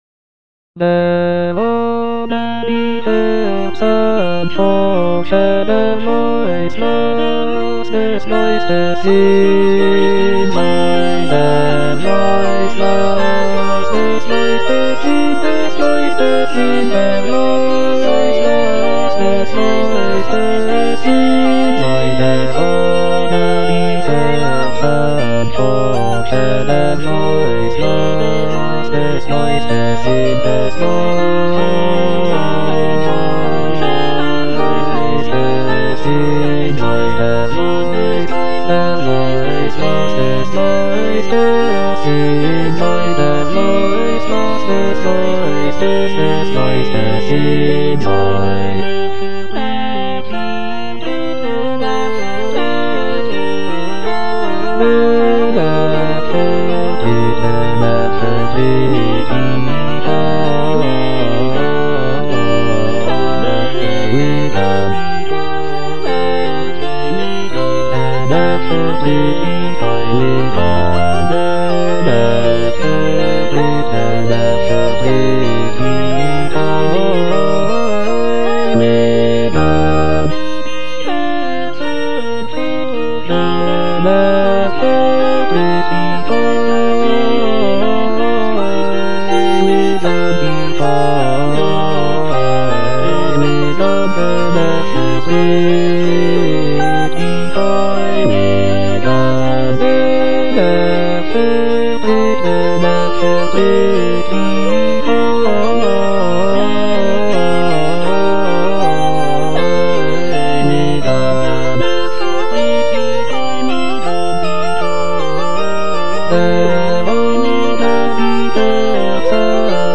J.S. BACH - DER GEIST HILFT UNSER SCHWACHHEIT AUF BWV226 Der aber die Herzen forschet - Bass (Emphasised voice and other voices) Ads stop: auto-stop
It is structured in seven parts for double choir, showcasing Bach's mastery of counterpoint and harmonic complexity.